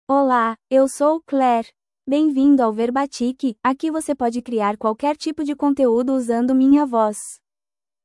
ClaireFemale Portuguese AI voice
Claire is a female AI voice for Portuguese (Brazil).
Voice sample
Listen to Claire's female Portuguese voice.
Female
Claire delivers clear pronunciation with authentic Brazil Portuguese intonation, making your content sound professionally produced.